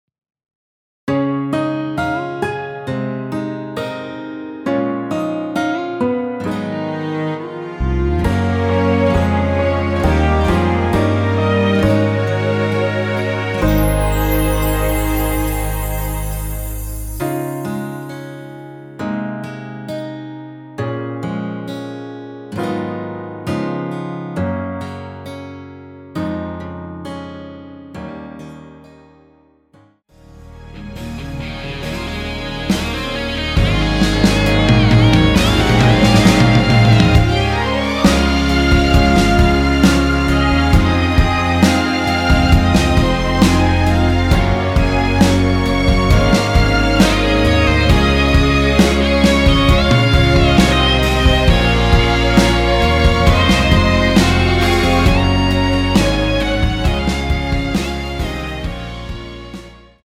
원키에서(-2)내린 MR입니다.
Db
앨범 | O.S.T
음질 좋습니다
앞부분30초, 뒷부분30초씩 편집해서 올려 드리고 있습니다.